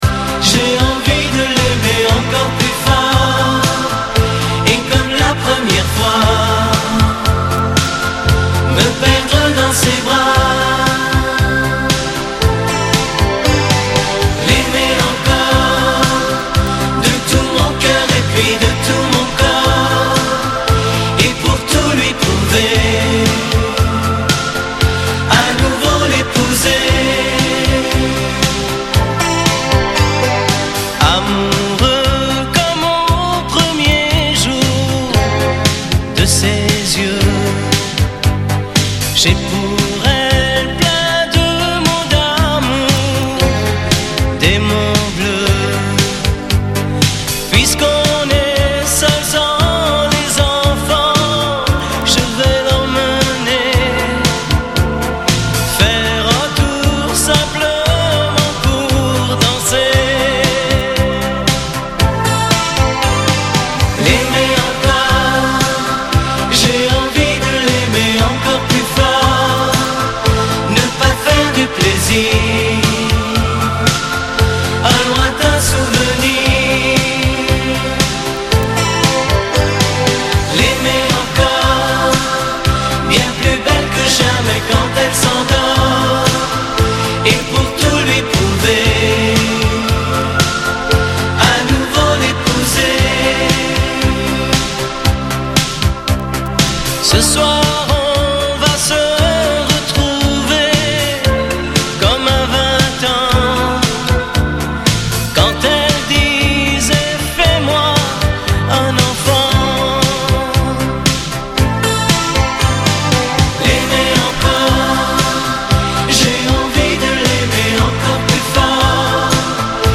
Dimanche Bonheur : musette, chansons et dédicaces